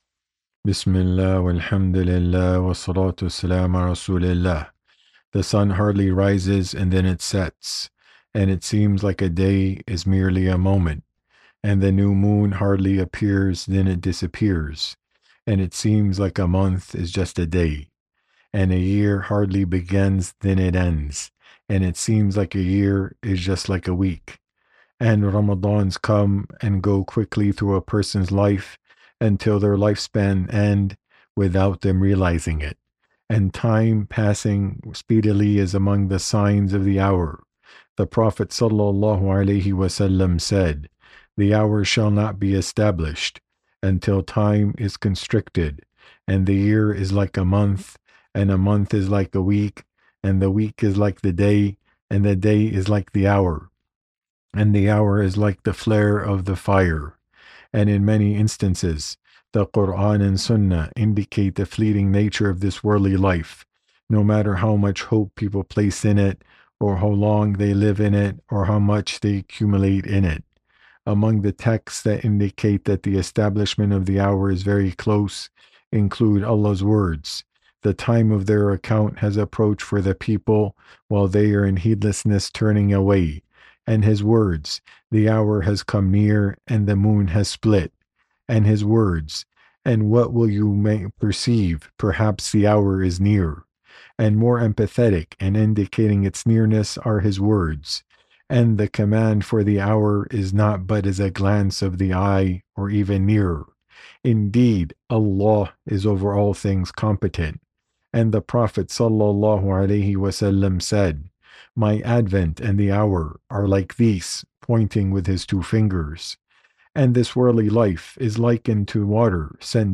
Friday Khutbah